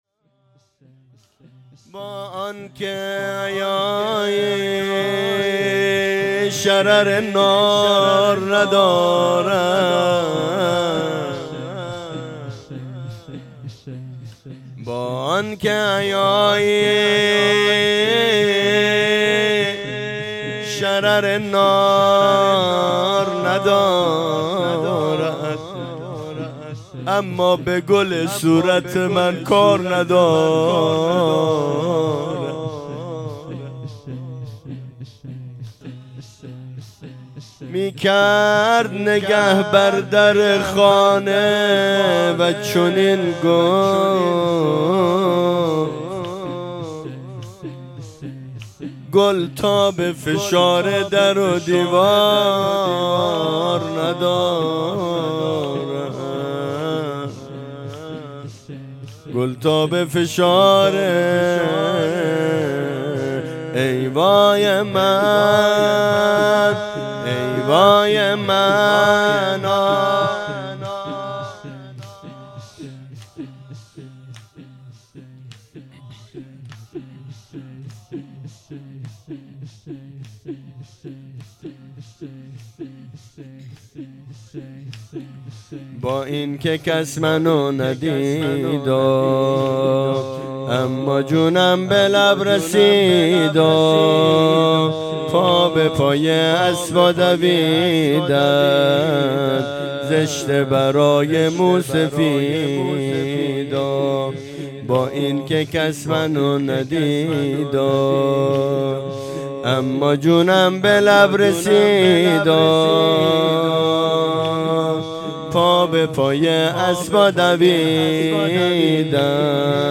ویژه مراسم شهادت حضرت امام جعفر صادق علیه السلام 1404